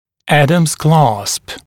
[‘ædəmz klɑːsp][‘эдэмз кла:сп]кламмер Адамса